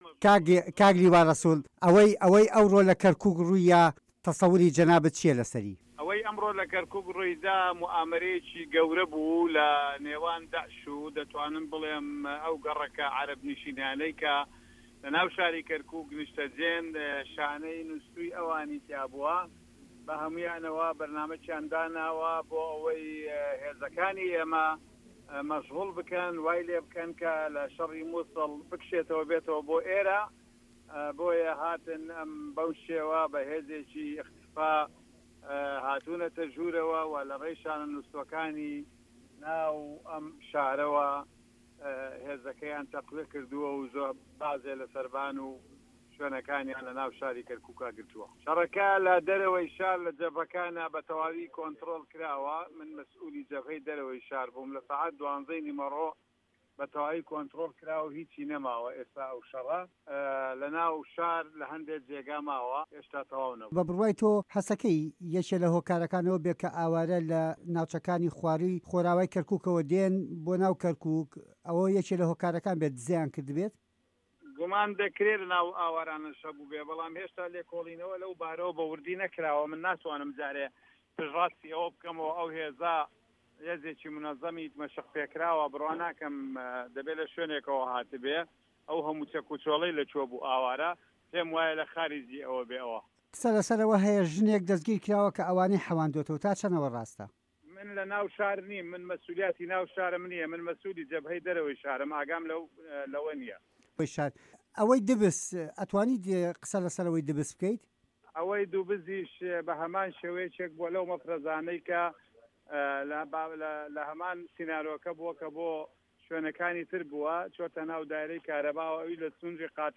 وتووێژ لەگەڵ لیوا ڕەسوڵ